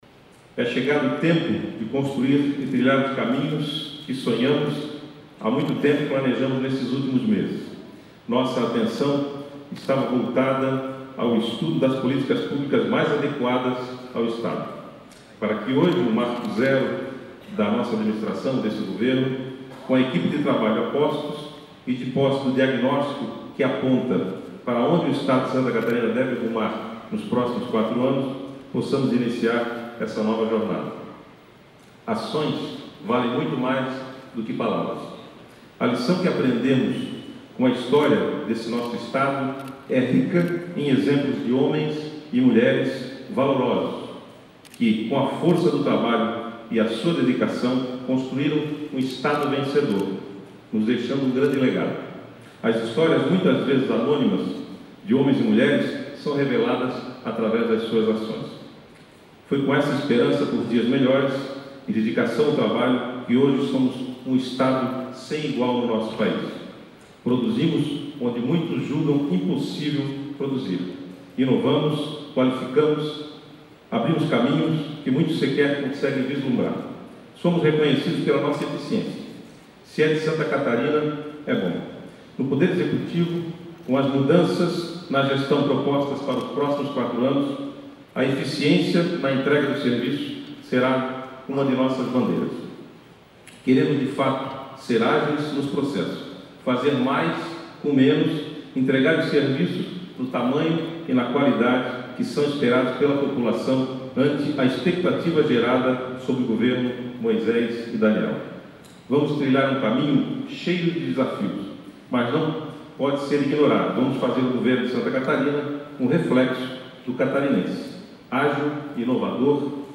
Discurso de posse do governador Moisés